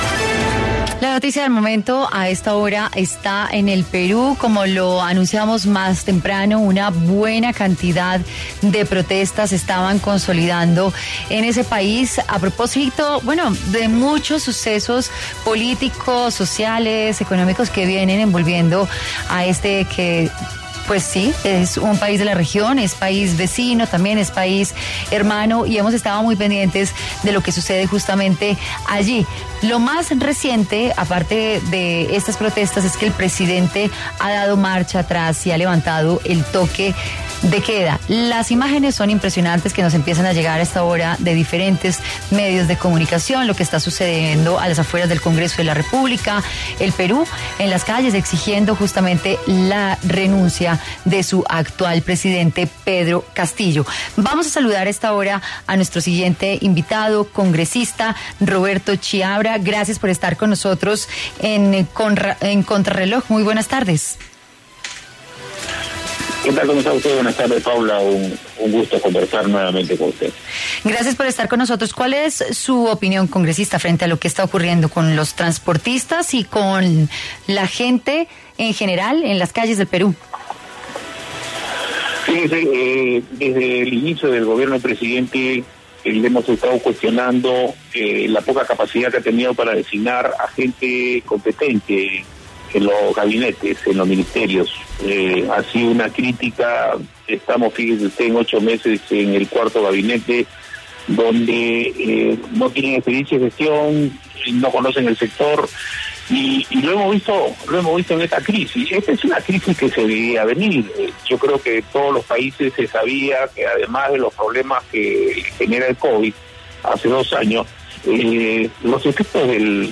Roberto Quiabra, congresista peruano, realizó un análisis a Contrarreloj sobre la crisis que vive Perú.
En dialogo con Contrarreloj de W Radio, el congresista peruano Roberto Quiabra, anunció que desde el inicio del gobierno de Castillo, se ha cuestionado la capacidad para asignar gente competente en los gabinetes.